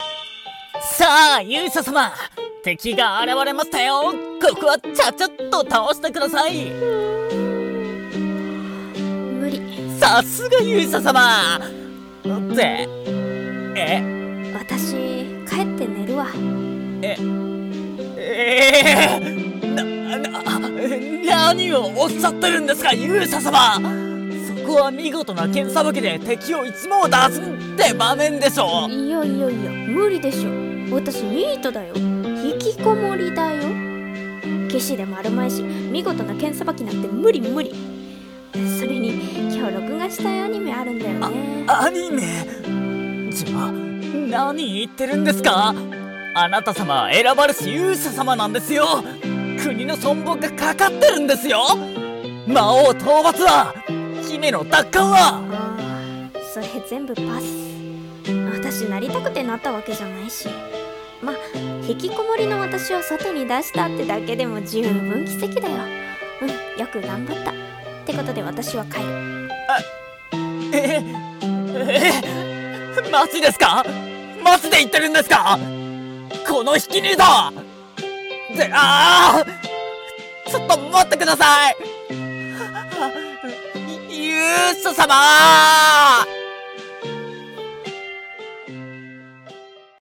【声劇】やる気のない勇者【掛け合い】